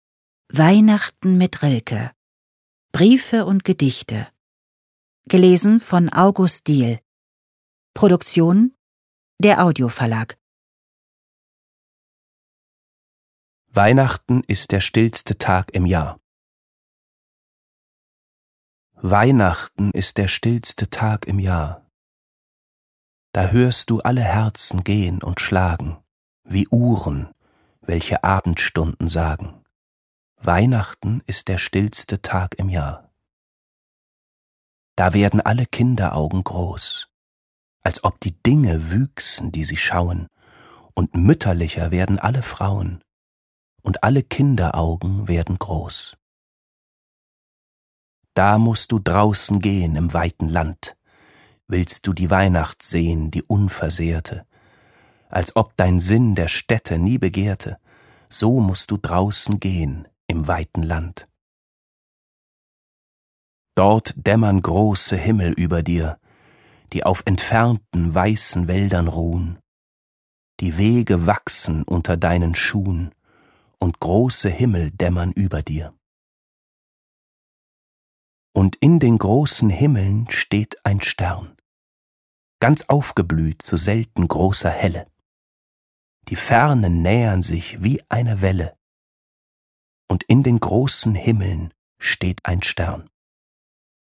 Sammlung der schönsten Weihnachtstexte von Rainer Maria Rilke. Atmosphärische Lesung mit August Diehl.